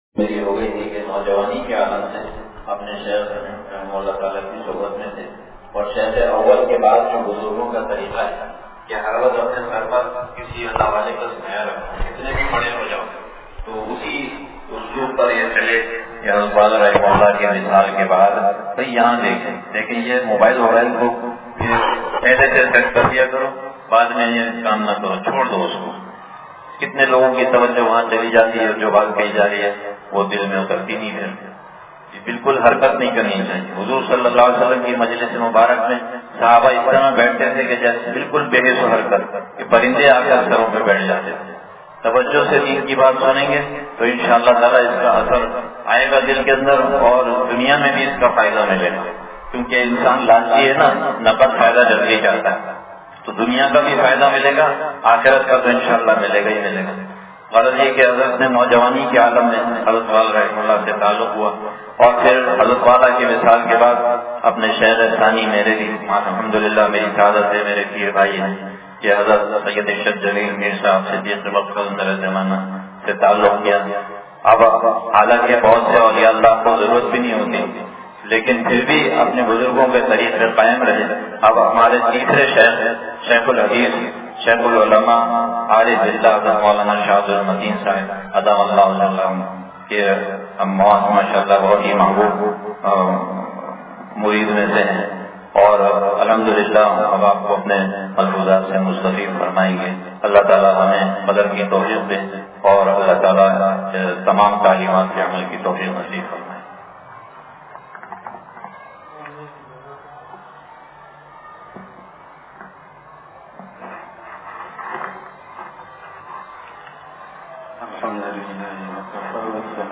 بیان بعد نمازظہر
مدرسہ ابو بکر مرکز امداد و اشرف نزد معمار ہاؤسنگ کراچی